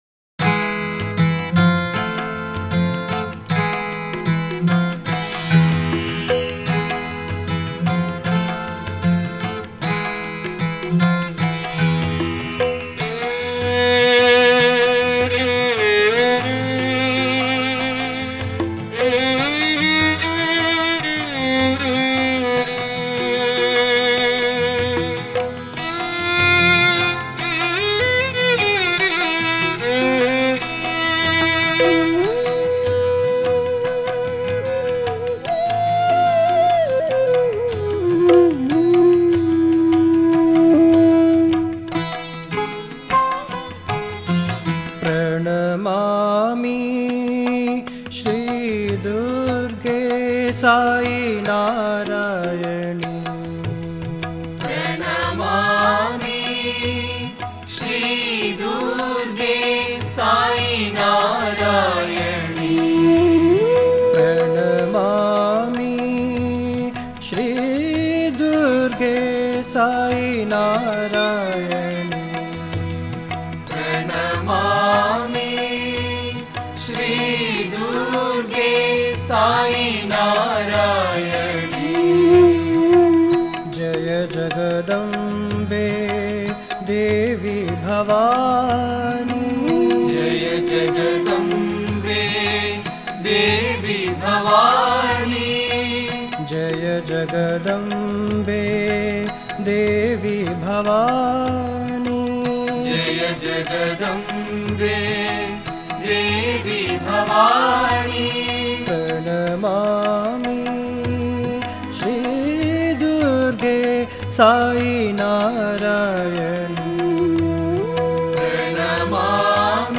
Home | Bhajan | Bhajans on various Deities | Devi Bhajans | 47 – PRANAMAMI SRI DURGE